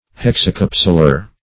Search Result for " hexacapsular" : The Collaborative International Dictionary of English v.0.48: Hexacapsular \Hex`a*cap"su*lar\, a. [Hexa- + capsular.]